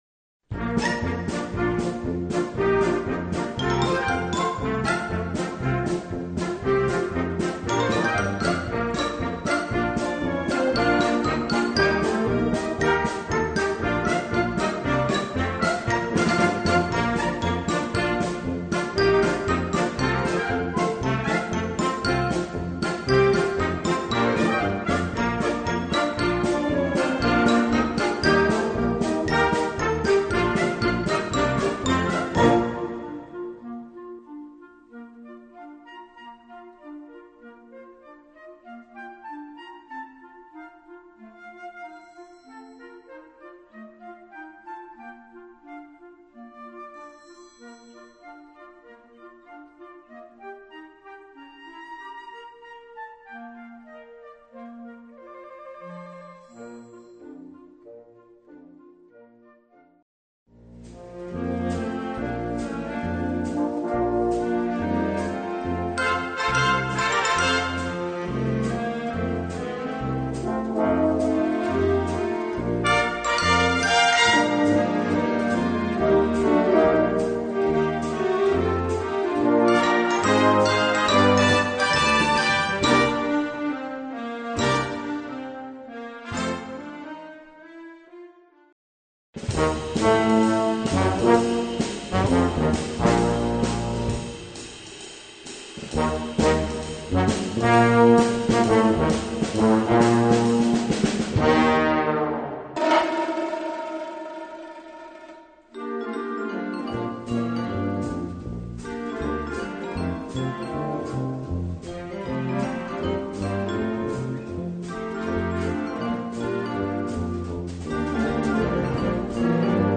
Partitions pour orchestre d'harmonie, ou - fanfare.
• View File Orchestre d'Harmonie
• View File Orchestre de Fanfare